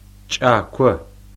кӏако like chat but ejective [1]
^ a b c d e f g h i j k l Ejective consonants, which do not occur in English, are voiceless consonants that are pronounced with pressure from the throat rather than the lungs.